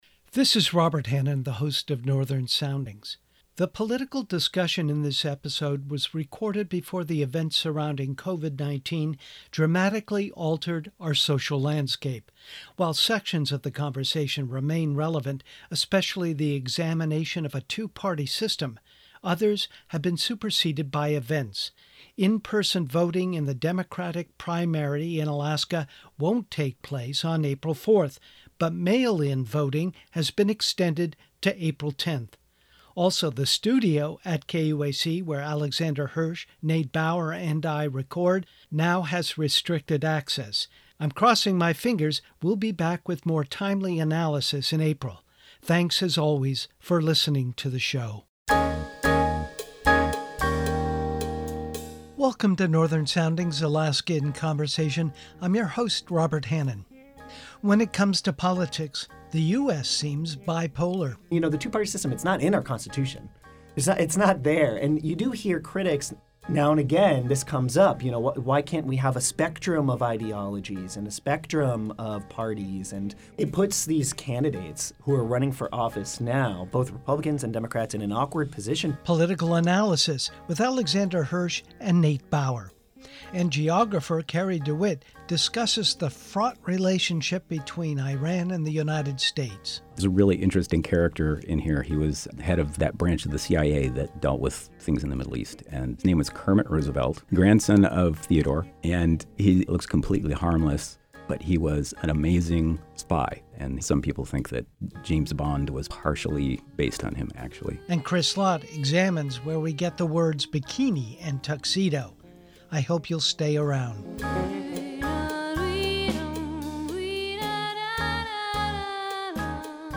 Segments for this show were recorded before events surrounding the viral pandemic COVID-19 radically transformed the social landscape across the globe. Consequently, some of the discussion points in the political segment are out of date.